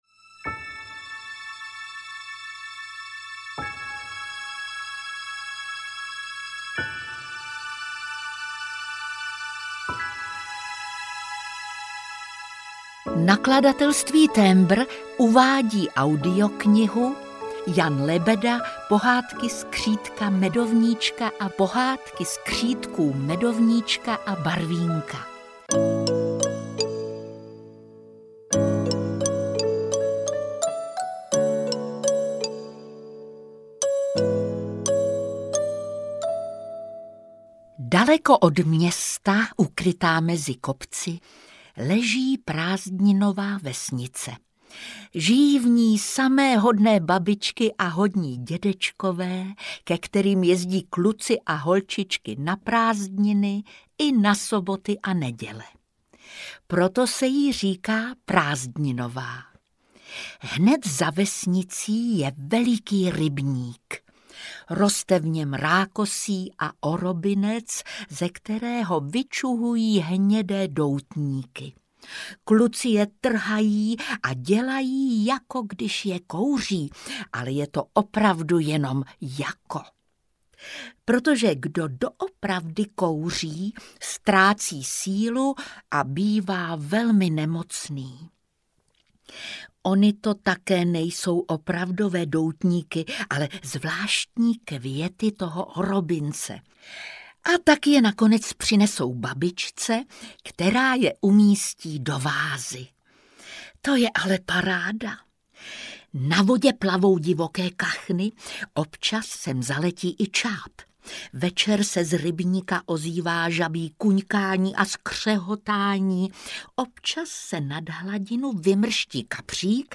Pohádky skřítka Medovníčka a Pohádky skřítků Medovníčka a Barvínka - Jan Lebeda - Audiokniha
• Čte: Jitka Molavcová